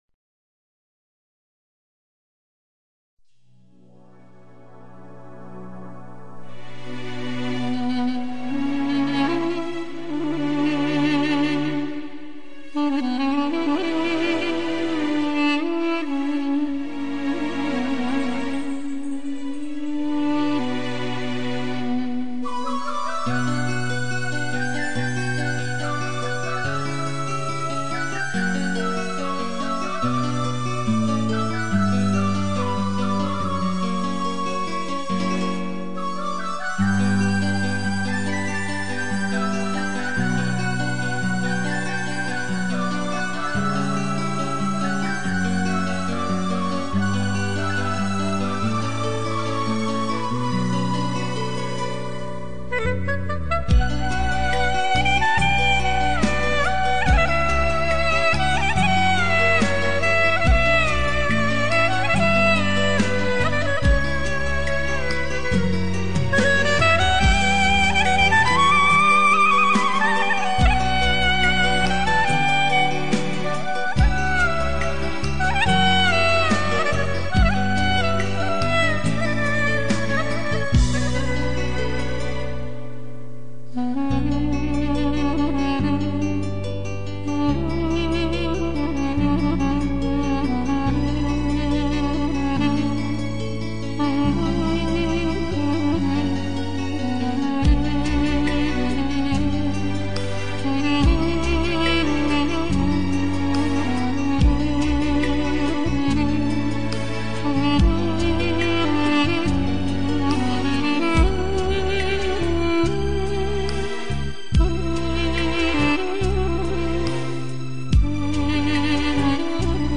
Дудук и Кларнет